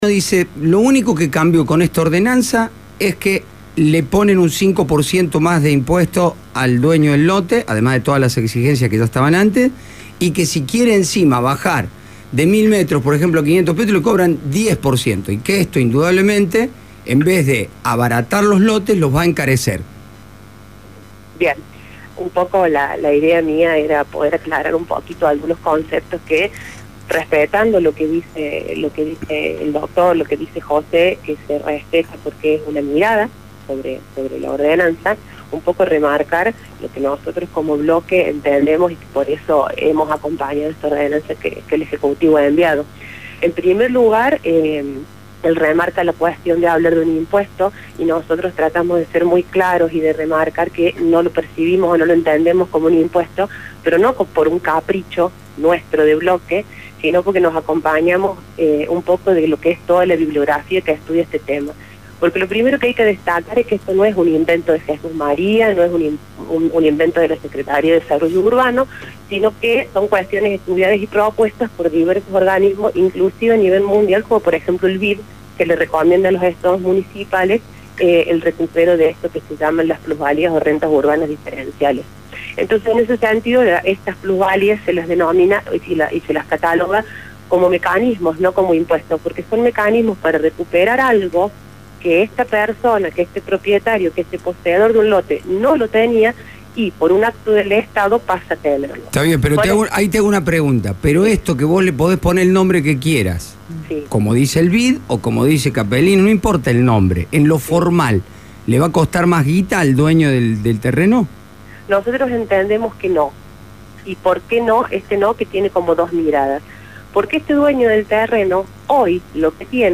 AUDIO: CONCEJALA OFICIALISTA, VERÓNICA BÚA.